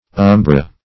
Umbra \Um"bra\, n.; pl. Umbrae.